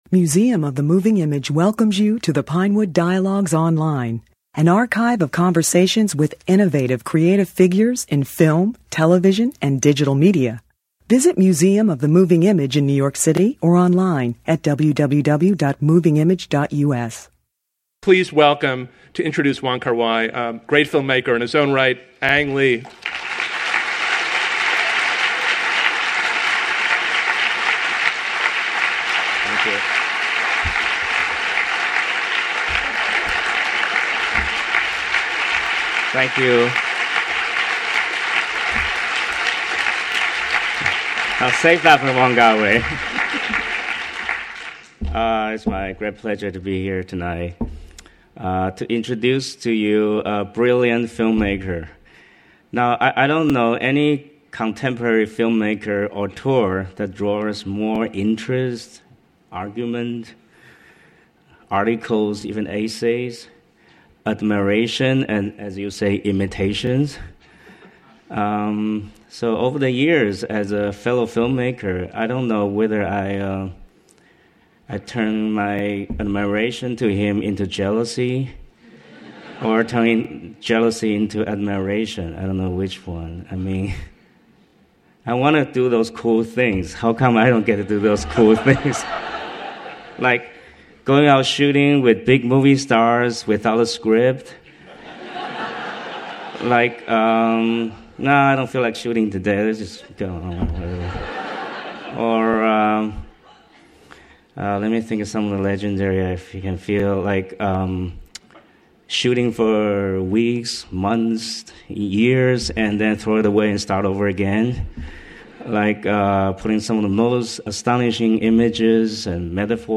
He spoke about his career at a Museum of the Moving Image program on the eve of the theatrical release of My Blueberry Nights (2007), his first English-language film. Academy Award-winning director Ang Lee introduced the evening.